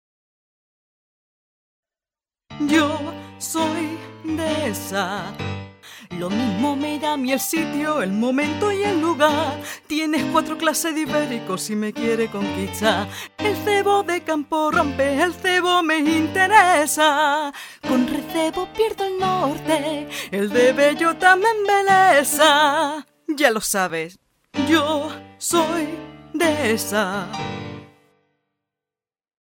Proyecto previo a la grabación musical del spot